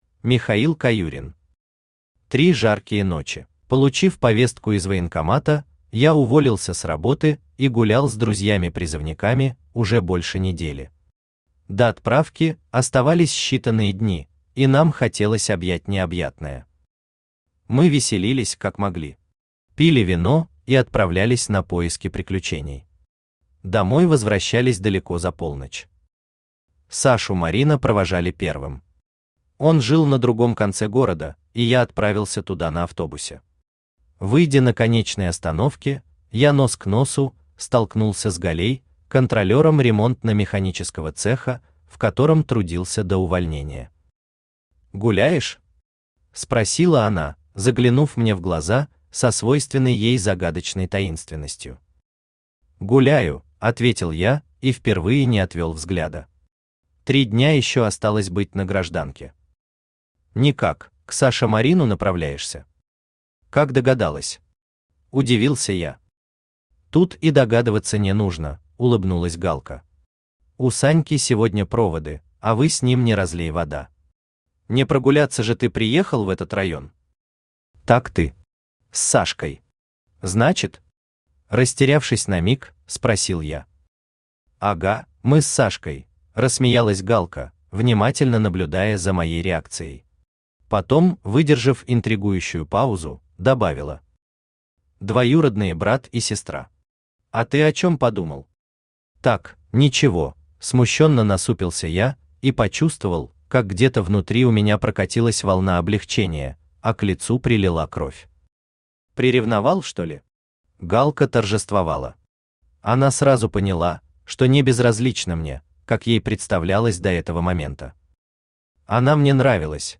Аудиокнига Три жаркие ночи | Библиотека аудиокниг
Aудиокнига Три жаркие ночи Автор Михаил Александрович Каюрин Читает аудиокнигу Авточтец ЛитРес.